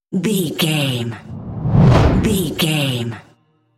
Whoosh deep fast
Sound Effects
Fast
dark
intense
sci fi